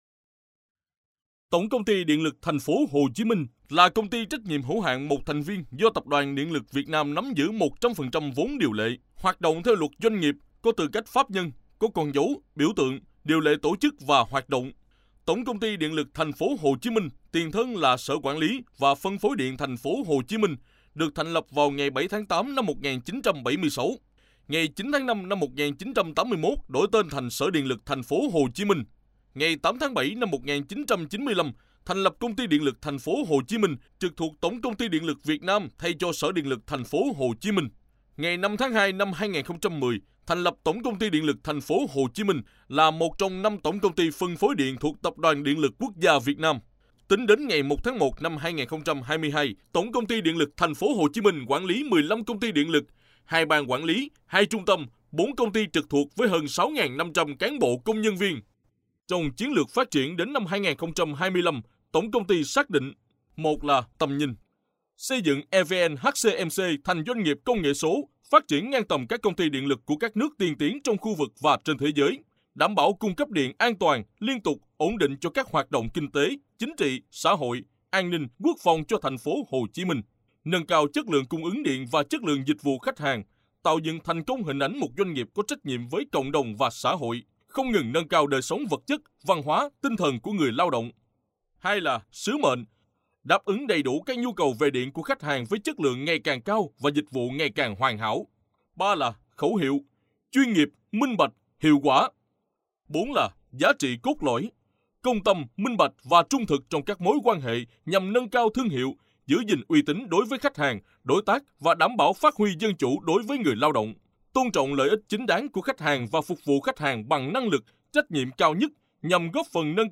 电台主播【大气稳重】